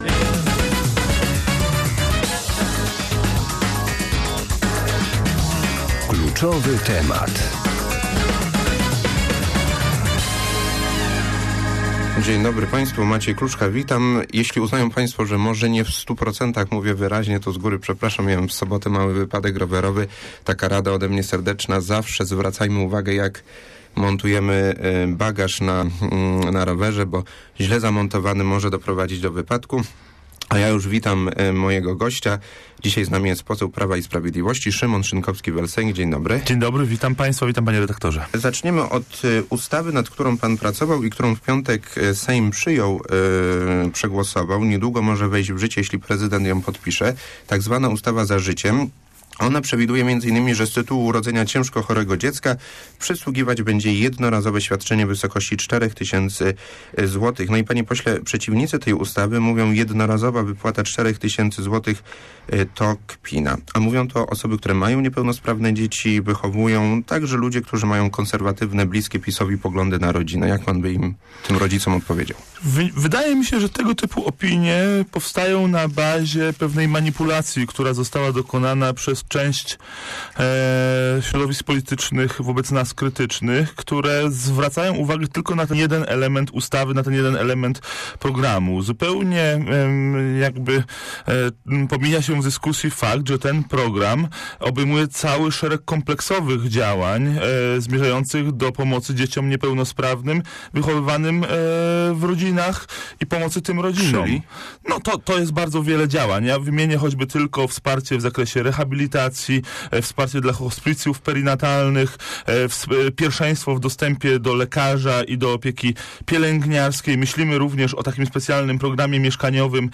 xbaj54w47a8mwcb_rozmowa_szynkowski.mp3